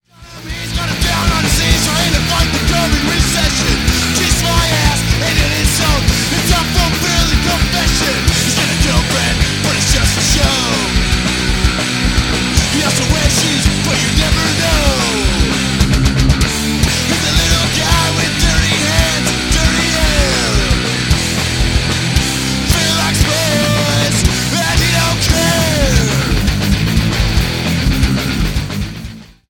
Thirteen in your face punk rock gems.